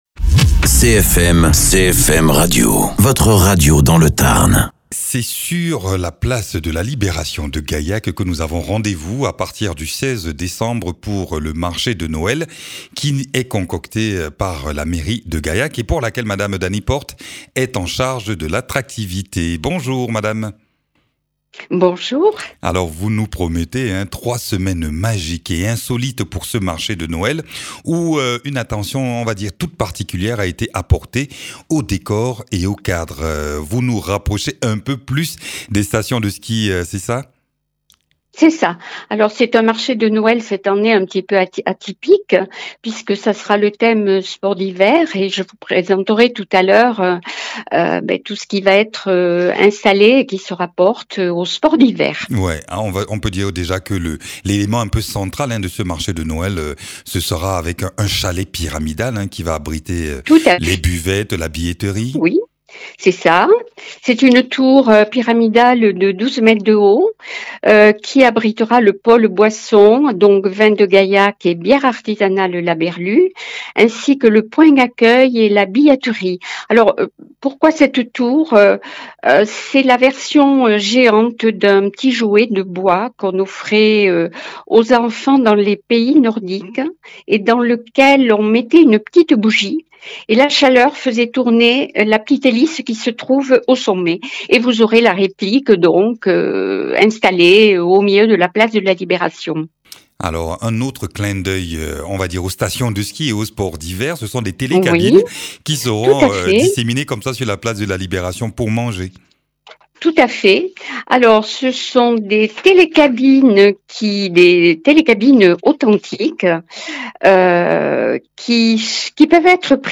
Interviews
Invité(s) : Dany Portes, élue de la ville de Gaillac en charge de l’attractivité.